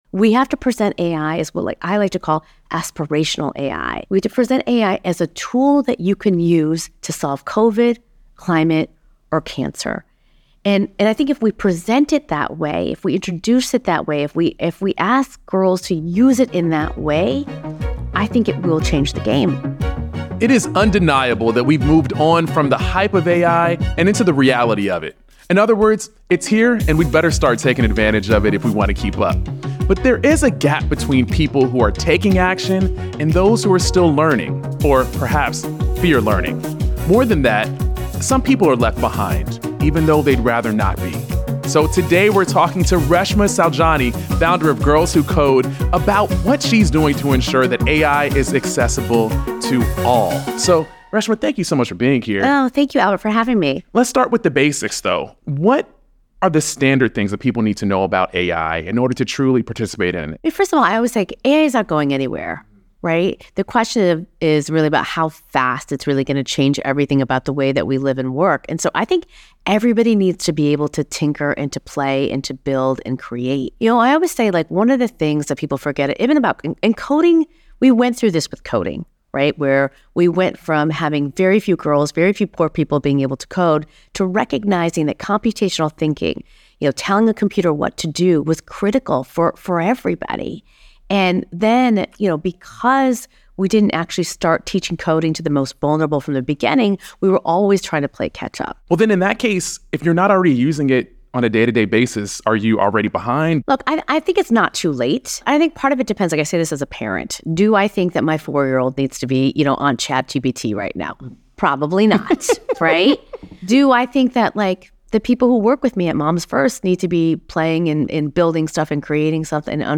Listen to Reshma Saujani, Founder & CEO of Girls Who Code, talk about “aspirational AI” and what we can do to close the gap.